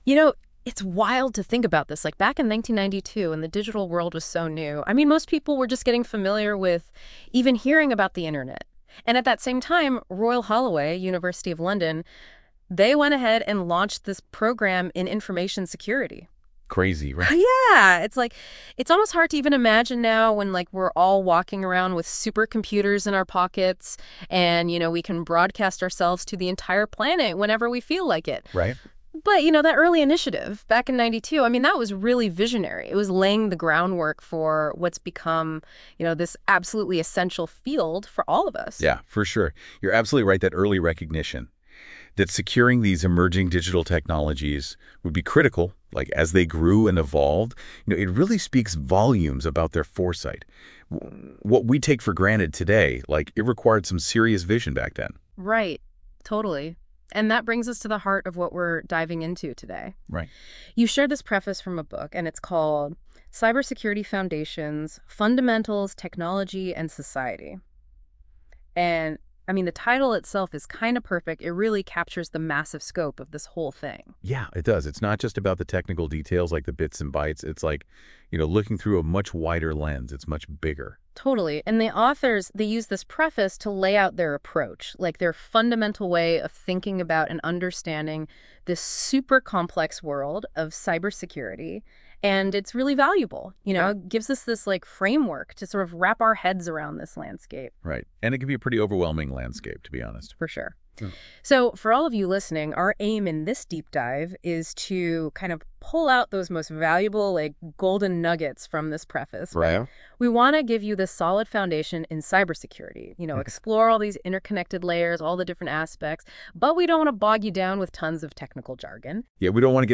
An AI audio overview of the book.